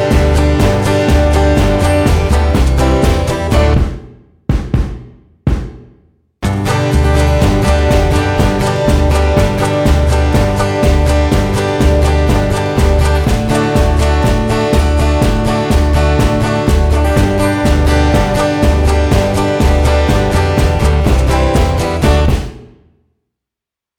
with joke replies Comedy/Novelty 3:27 Buy £1.50